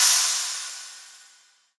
• Cymbal Sample G Key 06.wav
Royality free cymbal sound clip tuned to the G note. Loudest frequency: 7623Hz
cymbal-sample-g-key-06-6EC.wav